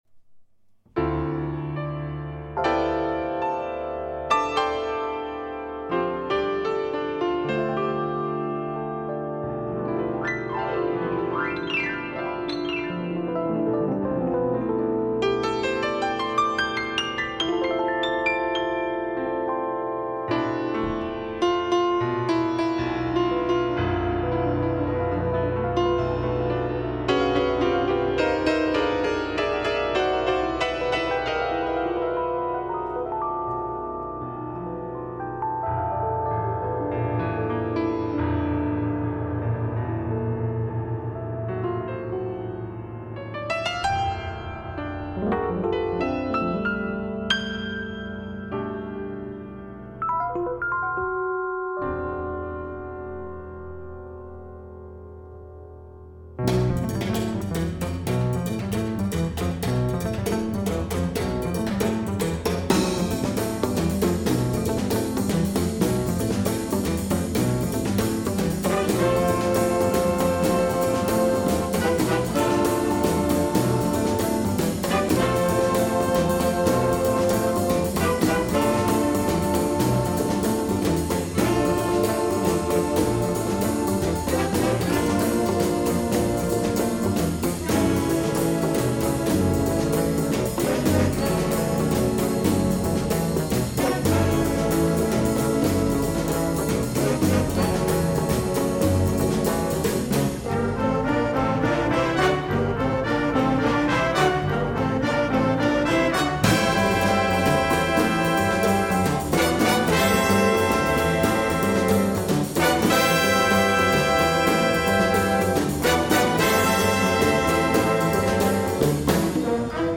percussioni
congas